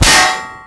ric_metal-2.wav